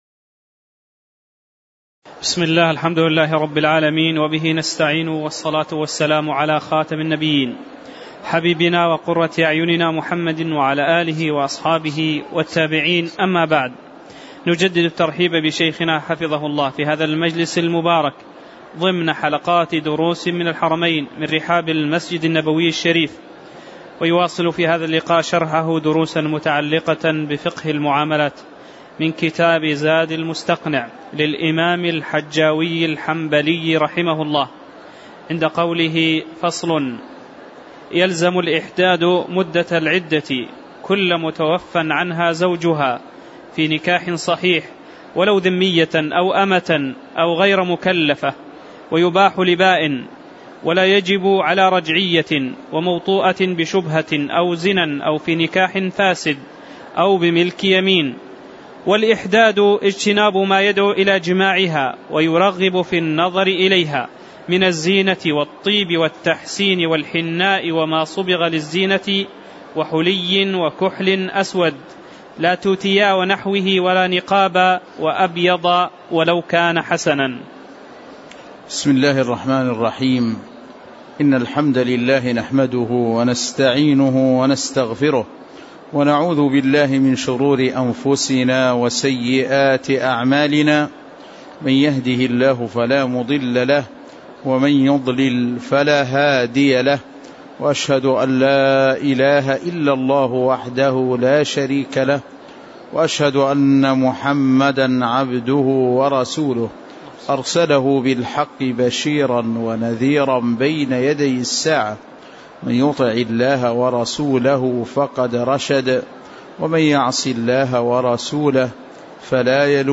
تاريخ النشر ٣٠ محرم ١٤٣٨ هـ المكان: المسجد النبوي الشيخ